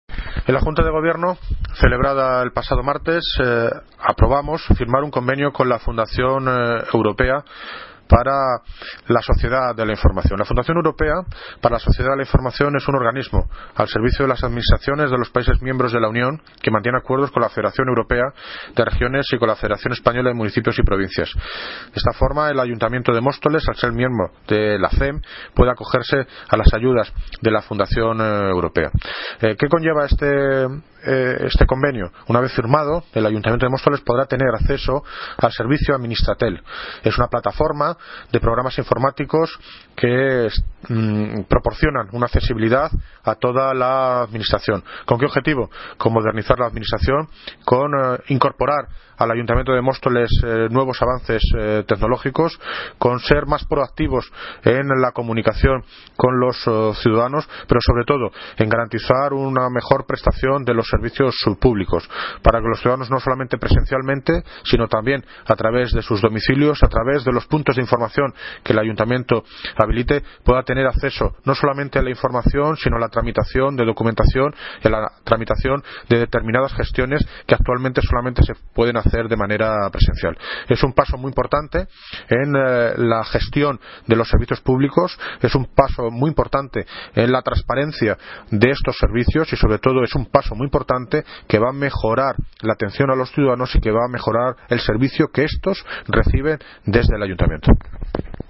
Audio - David Lucas (Alcalde de Móstoles) Sobre FUNDACION EUROPA